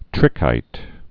(trĭkīt)